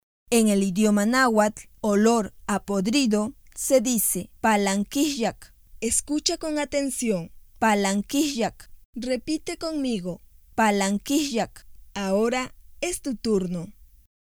En la lengua náhuatl de la variante de la Sierra de Zongolica, los olores tienen nombre y escritura, te invitamos a conocerlos y a estudiarlos, para lograr su correcta pronunciación.